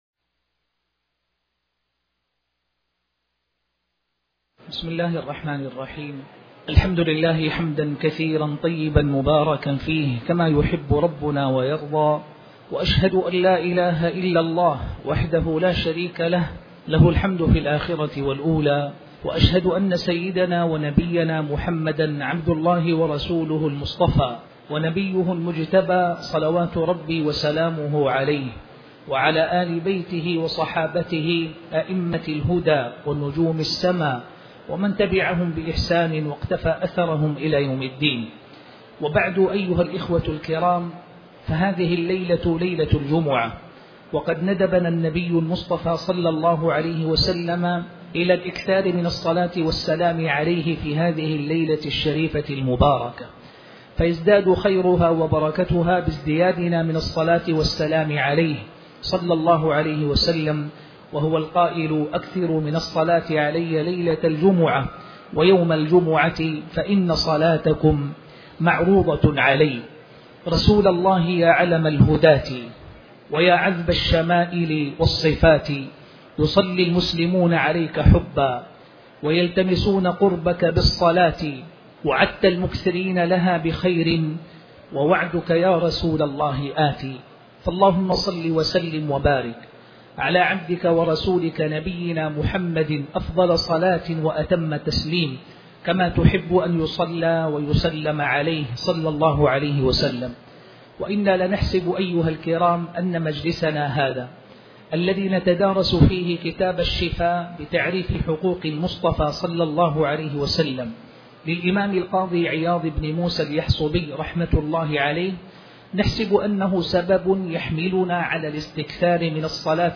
تاريخ النشر ٢ صفر ١٤٤٠ هـ المكان: المسجد الحرام الشيخ